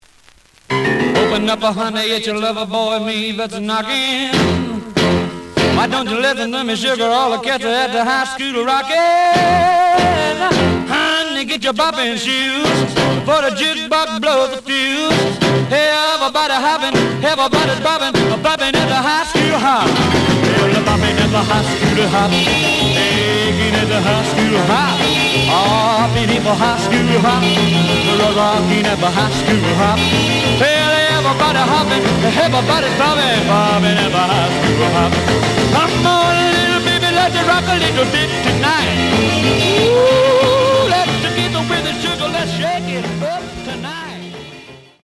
The audio sample is recorded from the actual item.
●Genre: Rhythm And Blues / Rock 'n' Roll
A side plays good.